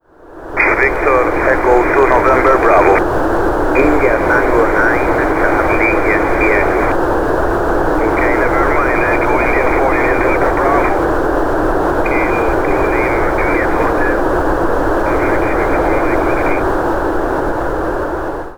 În următorul fișier sunt 5 indicative și zgomot de HF, fiecare indicativ cu 2db mai jos decât anteriorul. Dacă nu ai mai lucrat SSB, sau nu ai mai ascultat vreodată recepție de unde scurte, sunetul ar putea să te surprindă, dar cu răbdare și exercițiu, înregistrarea va deveni inteligibilă.
info:ssb-decode-test.mp3